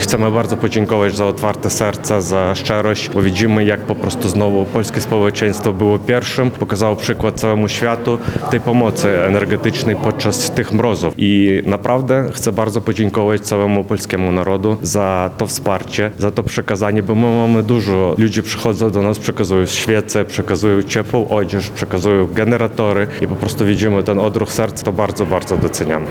Generalny konsul Ukrainy w Lublinie Oleh Kuts dziękuje Polakom za pomoc podczas kryzysu energetycznego.